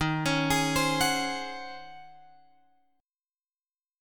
D# Minor 13th